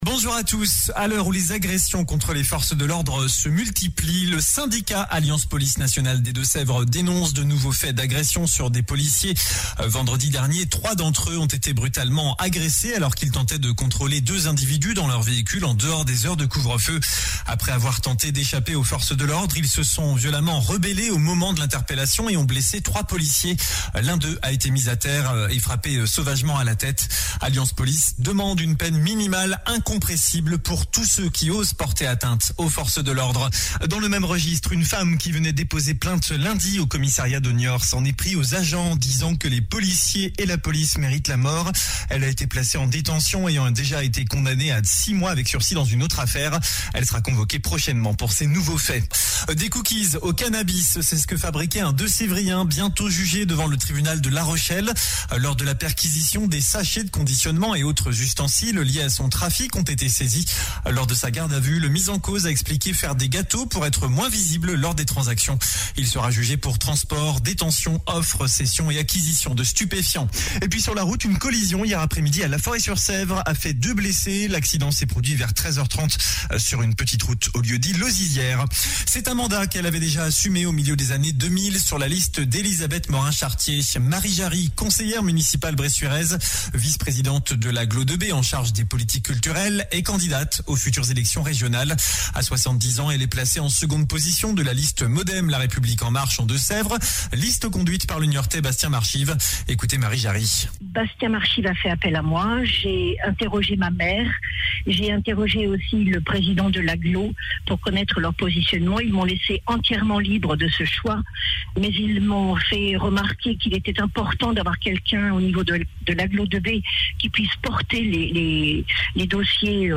Journal samedi 15 Mai 2021